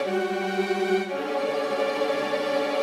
Index of /musicradar/gangster-sting-samples/85bpm Loops
GS_Viols_85-GD.wav